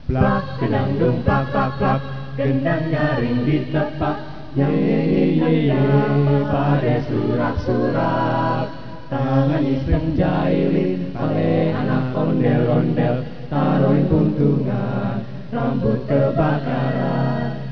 A Cappella Advisory : No Instruments
Indonesian Folk Song